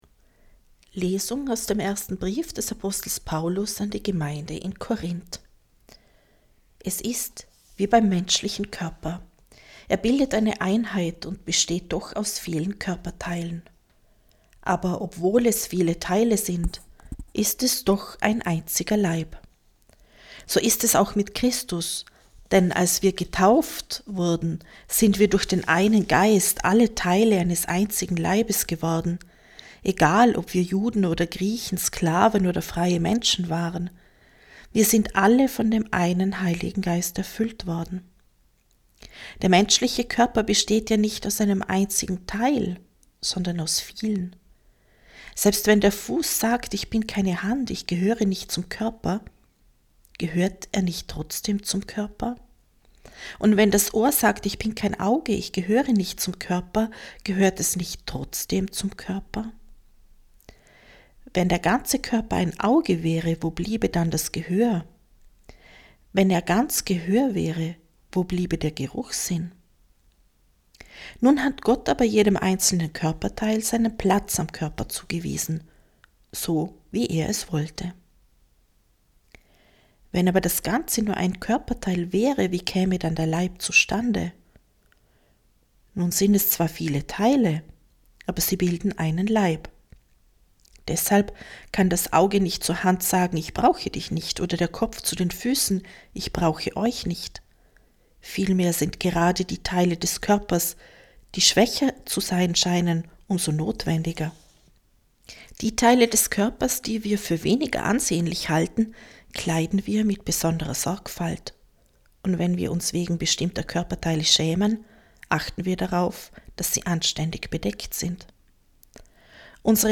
Wenn Sie den Text der 2. Lesung aus dem Brief des Apostels Paulus an die Gemeinde in Korínth anhören möchten:
2.-Lesung.mp3